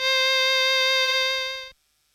DUSTY STRGS 1.wav